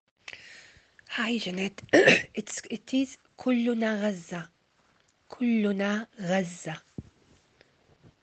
Song sheets and audio files – please note some of these are rough recordings, not full separate part soundfiles but will give you the idea!
Link to pronunciation help